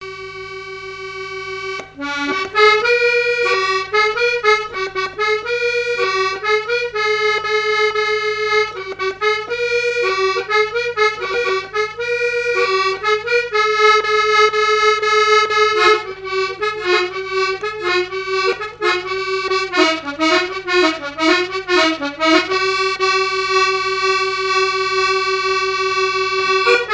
danse : ronde : grand'danse
accordéon diatonique et témoignages sur les musiciens
Pièce musicale inédite